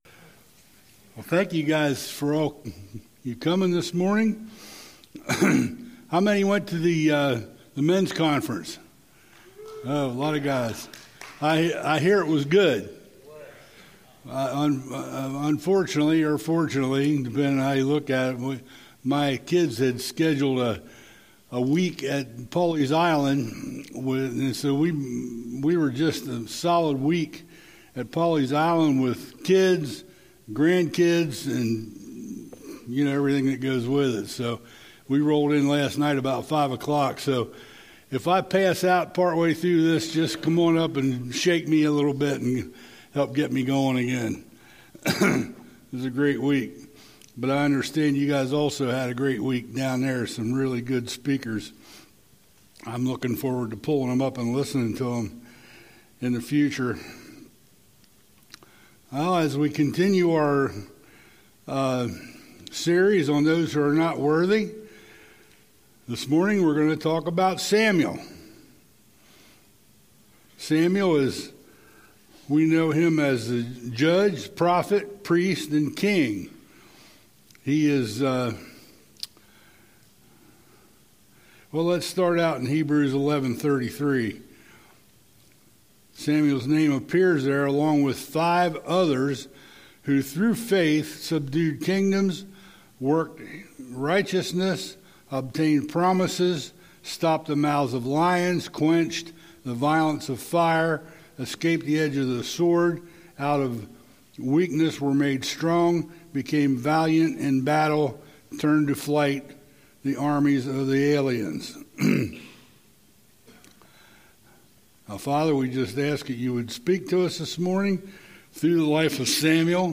A message from the series "Ironworks."